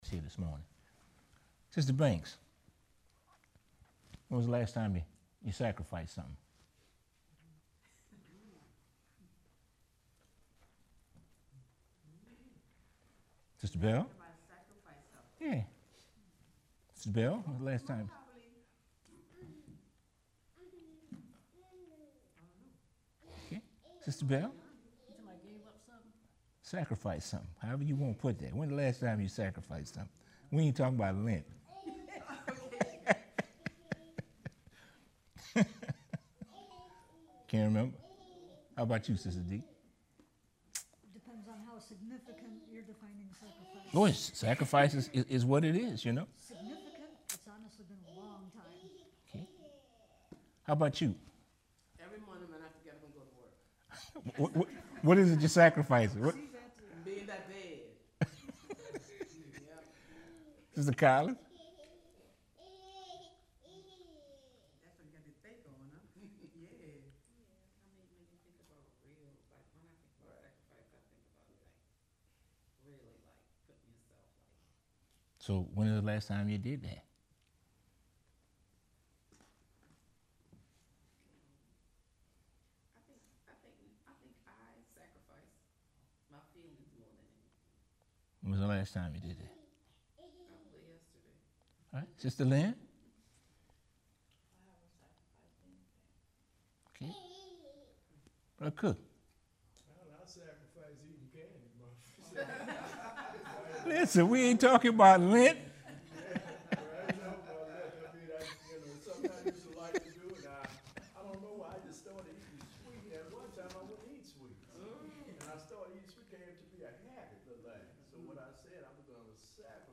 bible class